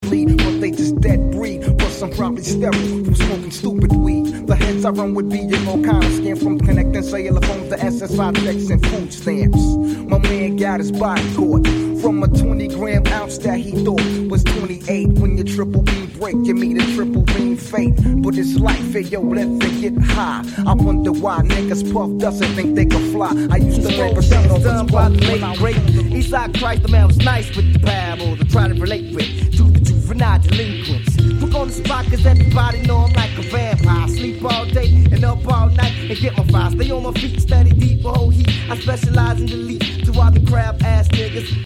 Mega rare tape version
jazzy hip-hop